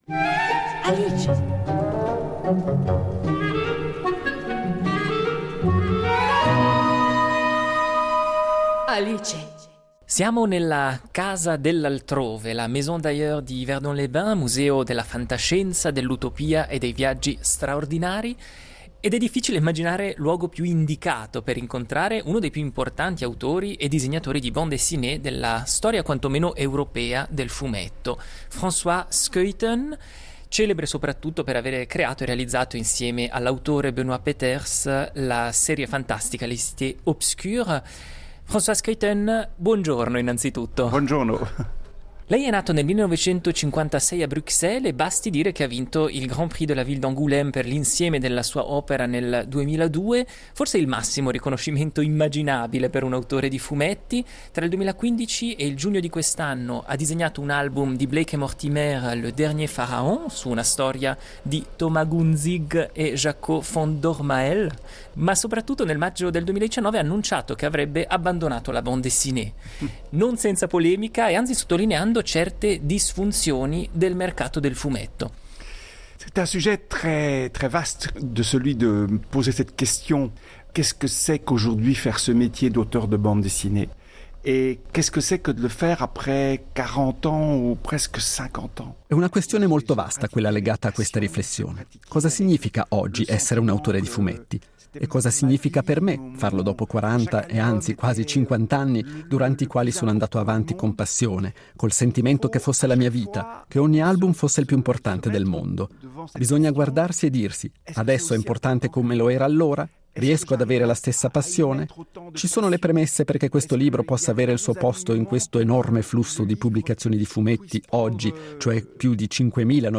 in occasione della mostra "Mondes (im)parfaits" alla Maison d'Ailleurs